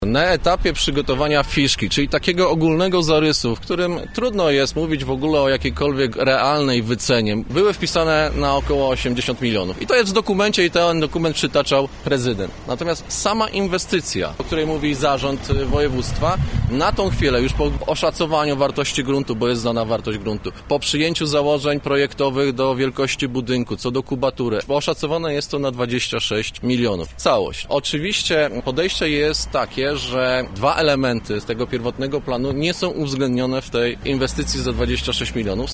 Prezydent powinien przestać przeszkadzać w prowadzeniu inwestycji – grzmieli dziś na konferencji prasowej radni miejscy Platformy Obywatelskiej.